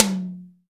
Wet Tom Drum Single Hit F# Key 11.wav
Royality free tom single hit tuned to the F# note. Loudest frequency: 4576Hz
wet-tom-drum-single-hit-f-sharp-key-11-03T.mp3